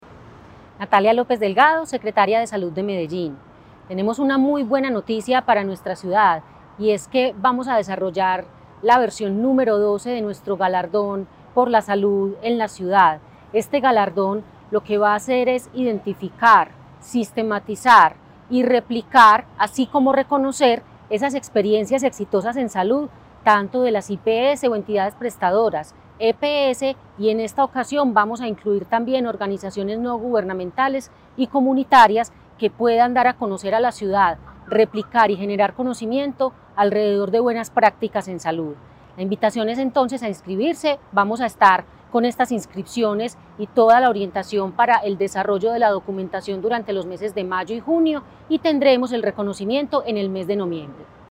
Audio-Declaraciones-de-la-secretaria-de-Salud-Natalia-Lopez-Delgado.mp3